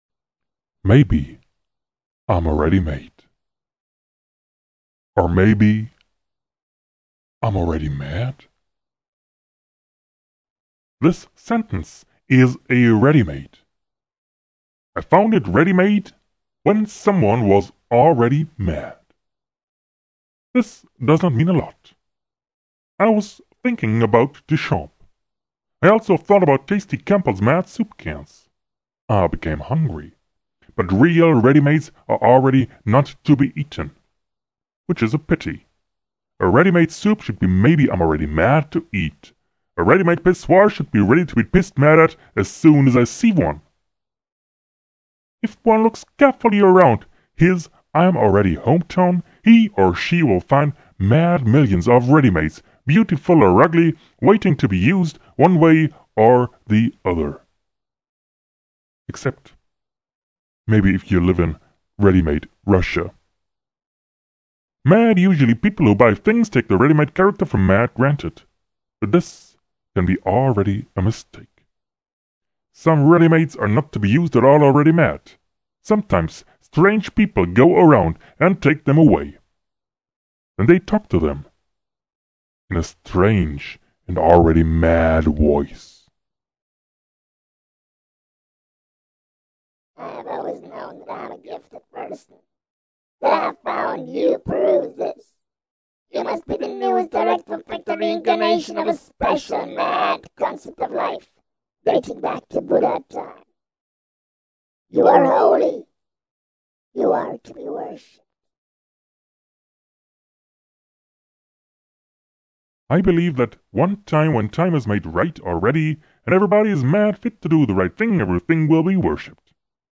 acoustic images from the word sound planet
this is just a voice translated into bits.
words are spoken.
all voices, boss sp 202 sampler, air fx infrared effect